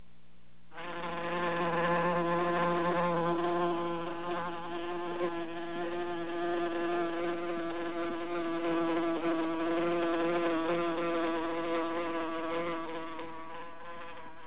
fly.au